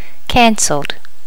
Additional sounds, some clean up but still need to do click removal on the majority.
cancelled.wav